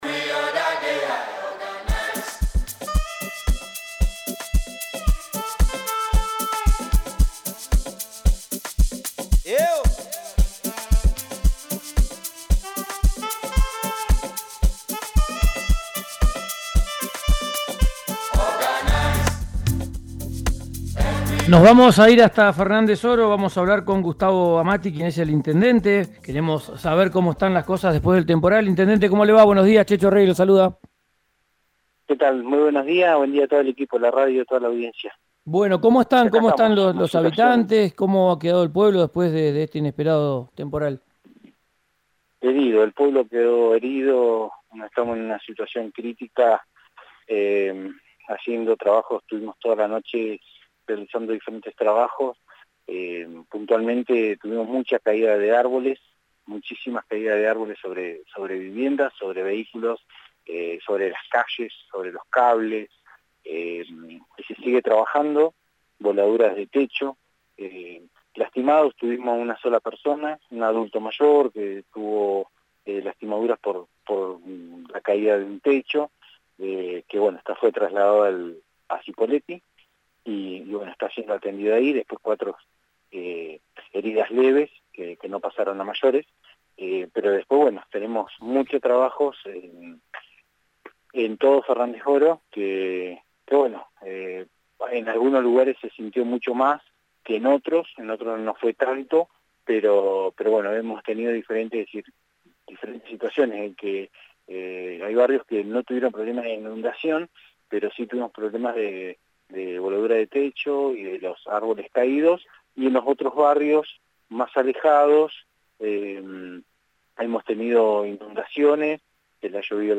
Escuchá al intendente de Fernández Oro, Gustavo Amati, en RÍO NEGRO RADIO
En diálogo con RÍO NEGRO RADIO, el intendente de Fernández Oro, Gustavo Amati, además lamentó que el temporal lesionó a otras cuatro personas, pero de manera leve.